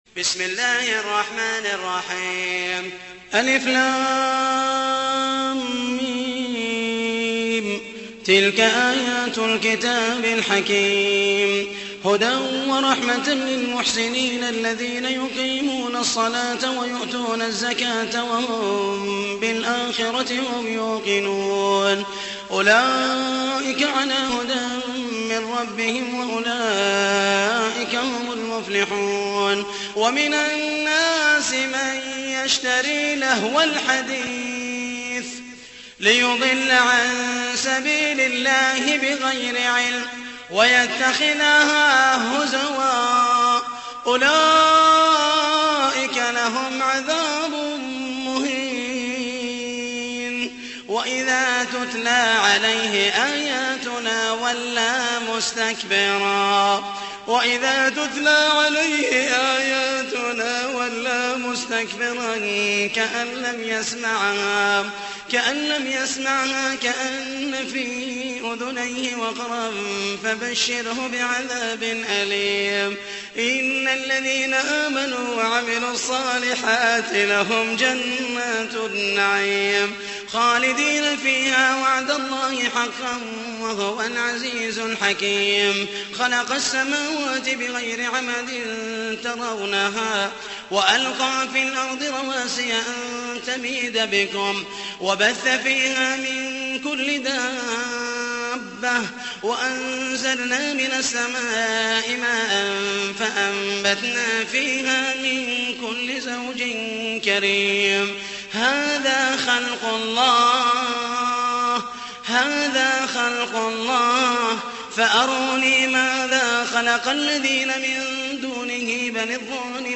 تحميل : 31. سورة لقمان / القارئ محمد المحيسني / القرآن الكريم / موقع يا حسين